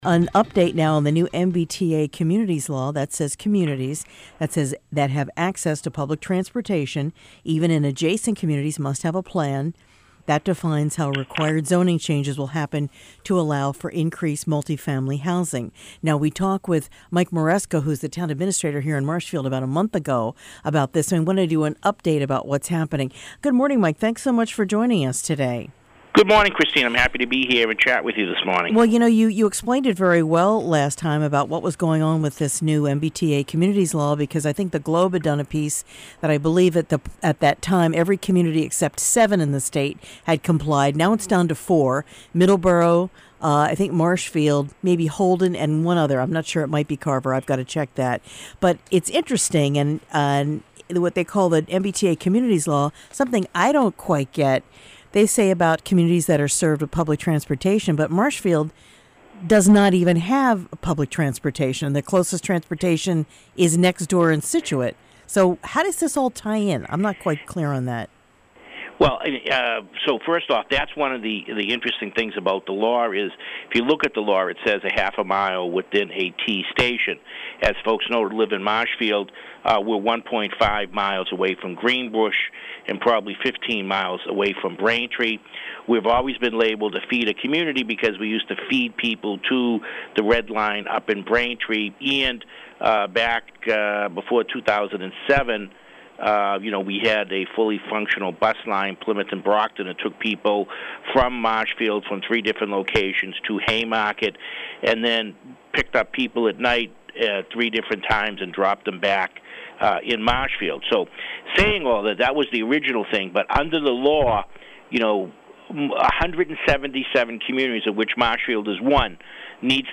Town Administrator, Michael Maresco